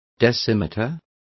Complete with pronunciation of the translation of decimeters.